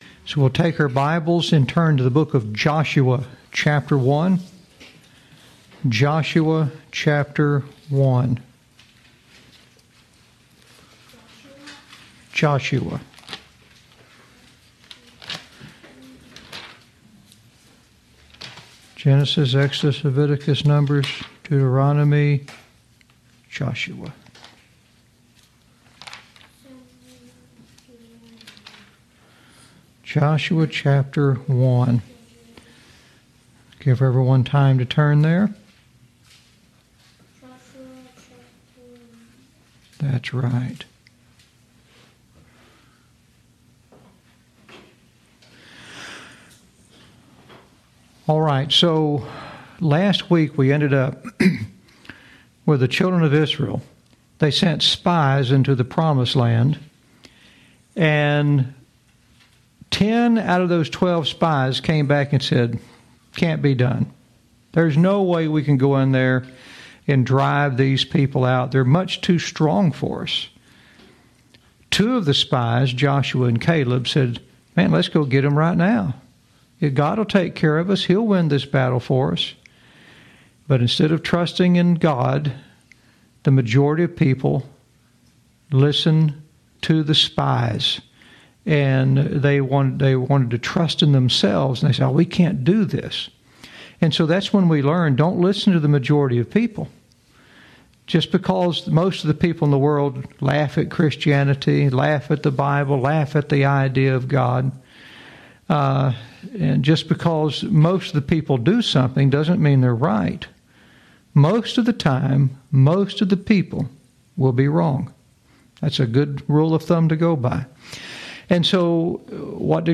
Lesson 27